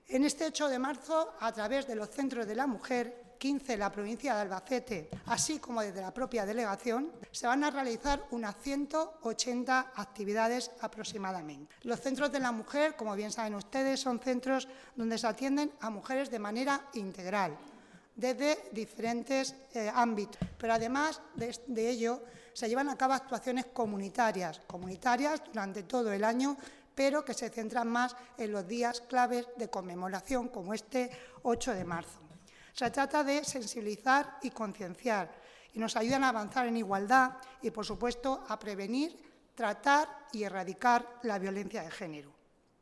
Lola Serrano, delegada de Igualdad en Albacete